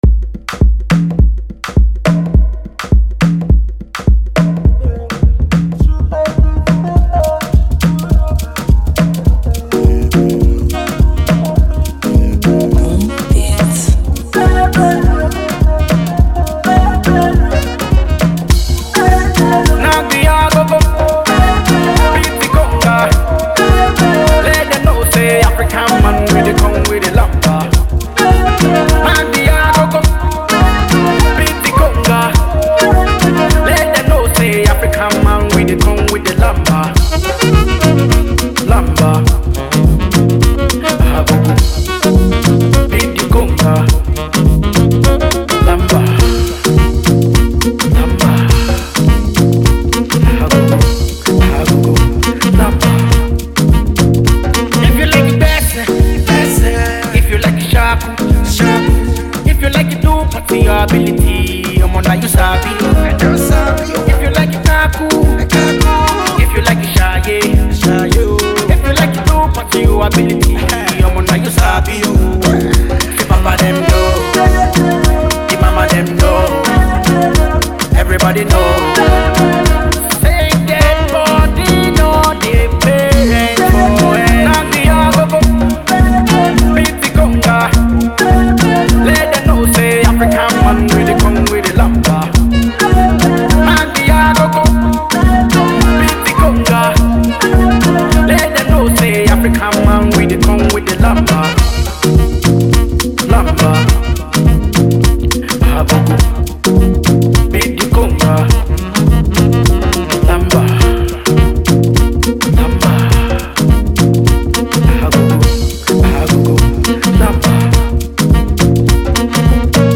Upcoming Nigeria/German singer
classic Afro tune